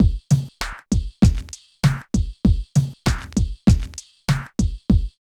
71 DRUM LP-R.wav